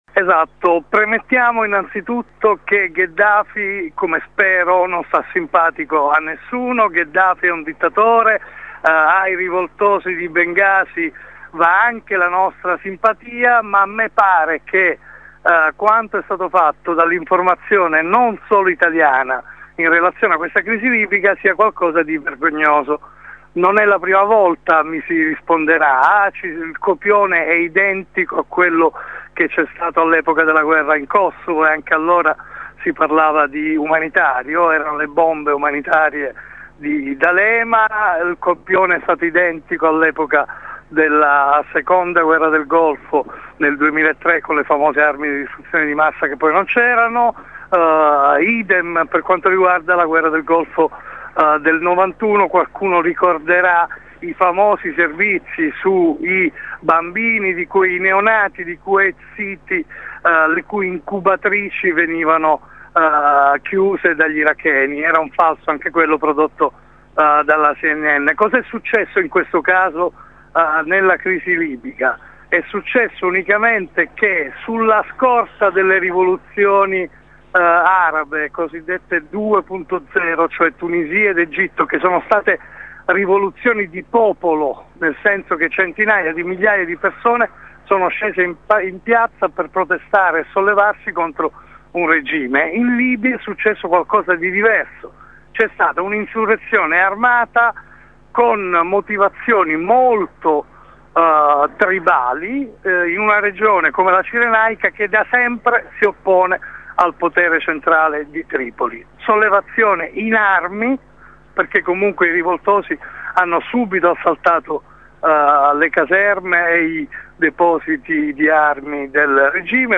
Non solo per ragioni di propaganda delle parti in causa, ma per il conformismo e il sensazionalismo di cui si nutrono i media. Ascolta l’intervista